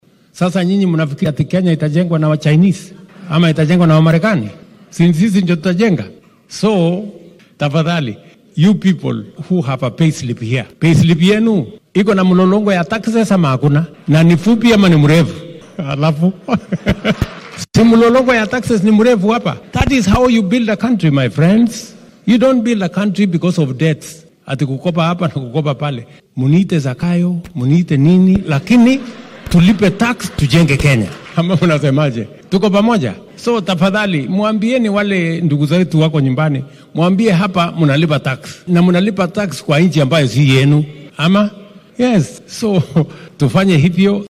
Madaxweynaha dalka William Ruto oo magaalada Atlanta kula hadlayay qurba joogta kenyaanka ah ee ku nool Maraykanka ayaa difaacay canshuurta dheeraadka ah ee uu maamulkiisa soo rogay. Waxaa uu hoosta ka xarriiqay in ujeeddada laga leeyahay ay tahay in Kenya ay horumar gaarto iyadoo adeegsanaysa dhaqaalaheeda oo aan ku tiirsanayn amaah shisheeye.